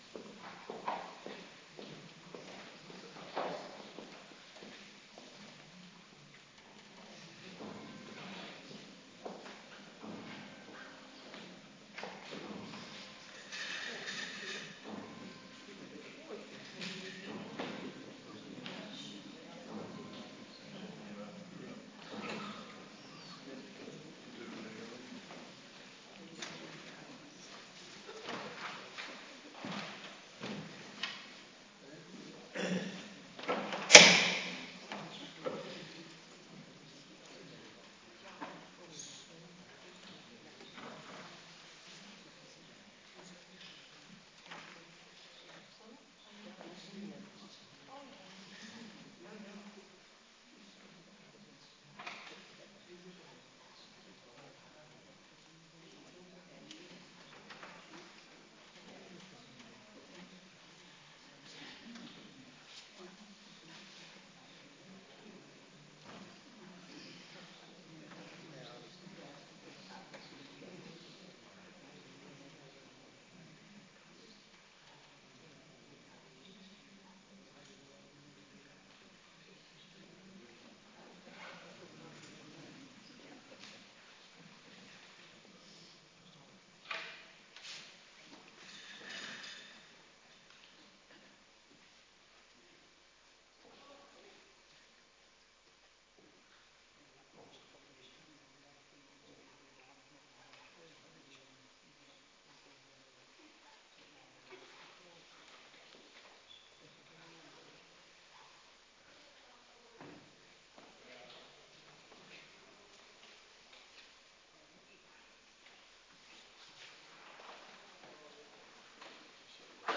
“STILLE WEEK” AVONDGEBED
Organist